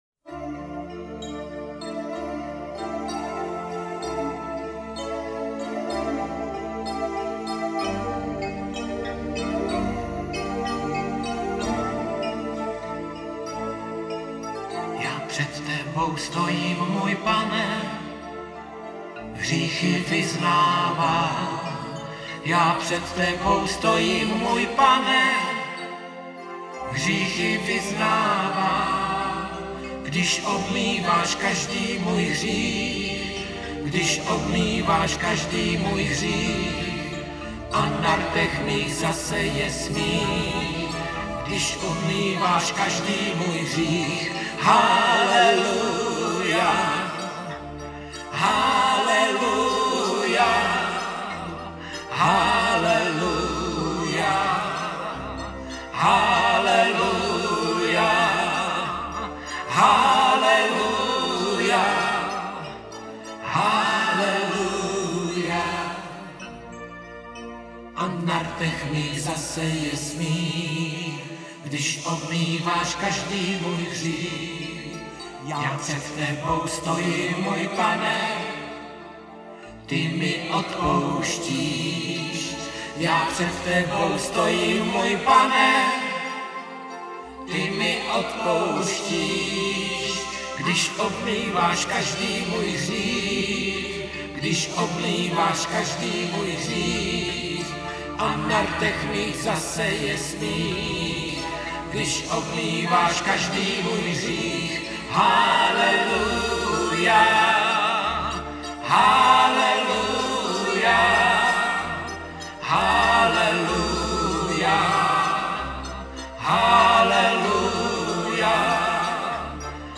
Křesťanské písně
Písničky pro děti